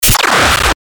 FX-1856-BREAKER
FX-1856-BREAKER.mp3